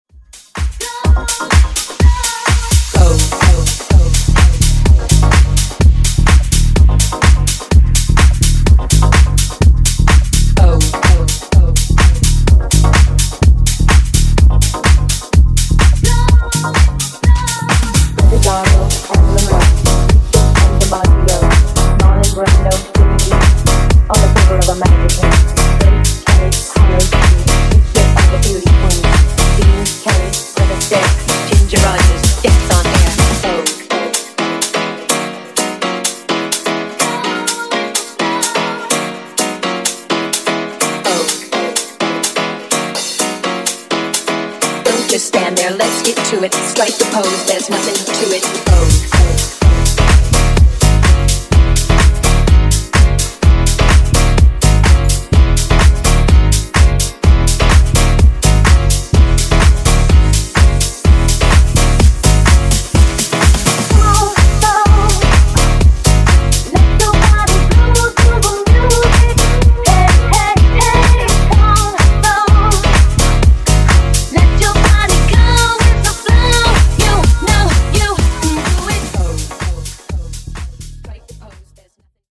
Genre: BOOTLEG
Clean BPM: 126 Time